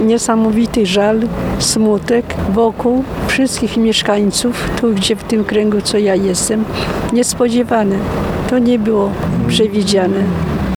Ten dzień wspomina jedna z ełczanek, obecna na uroczystościach upamiętniających ofiary.